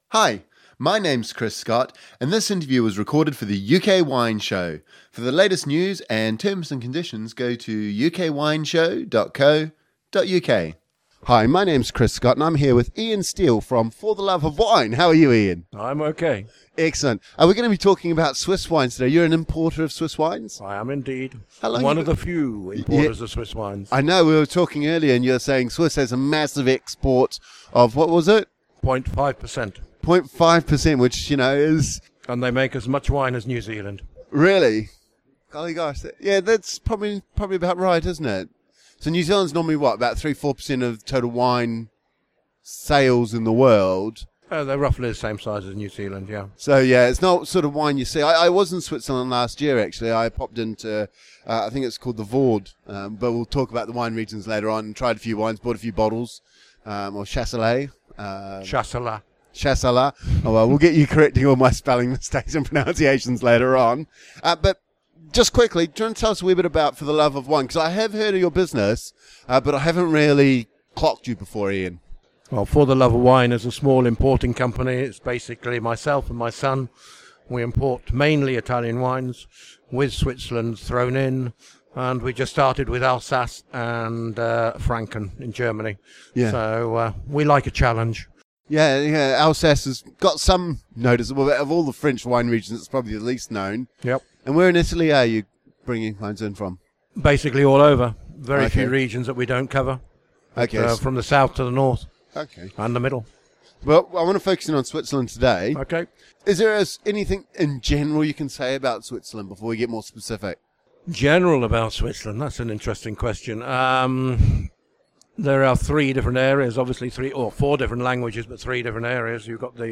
In this interview we focus on Switzerland and its main wine regions which span the different speaking areas from French-speaking to Italian and German. The main white wine is Chasselas accounting for much of the production and which shows as very many different expressions from the different regions.